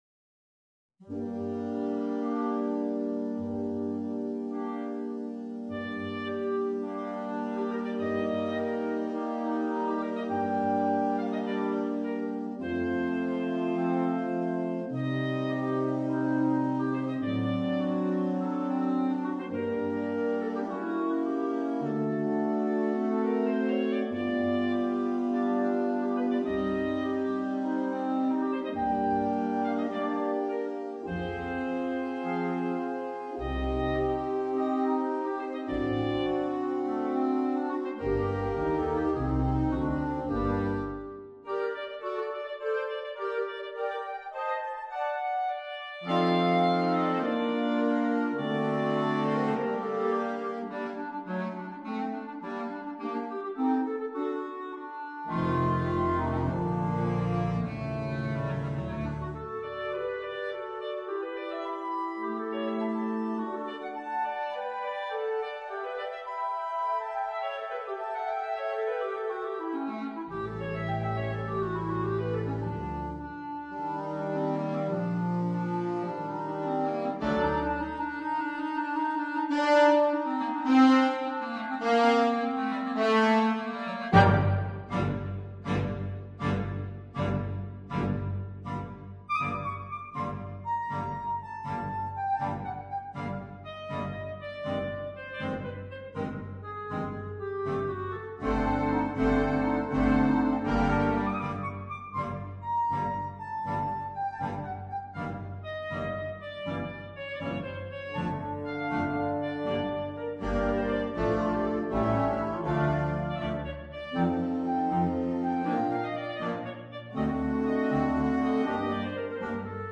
per clarinetto e banda